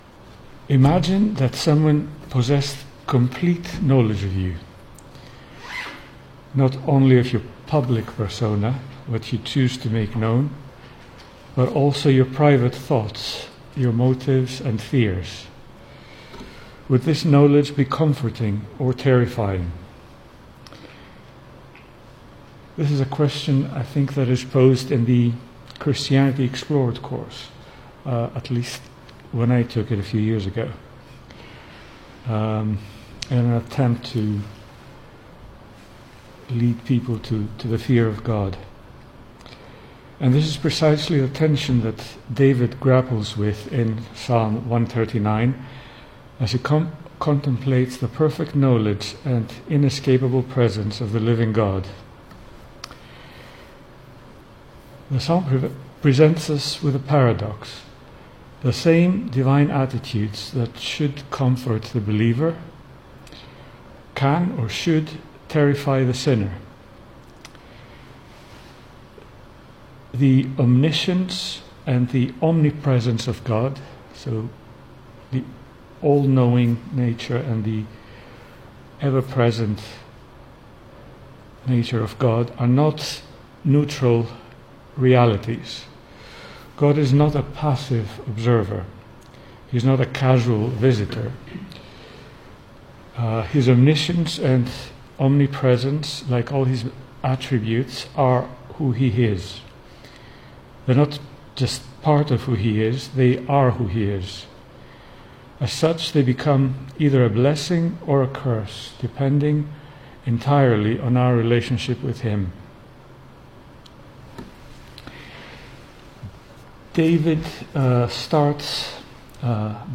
Service Type: Weekday Evening
Single Sermons